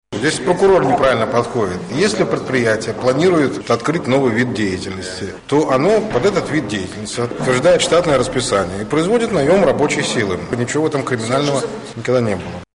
з архіву "Свабоды"